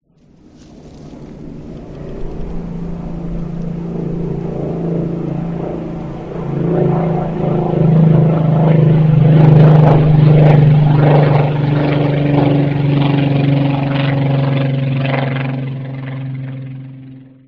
Avioneta
Avioneta.mp3